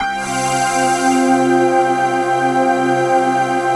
XXL 800 Pads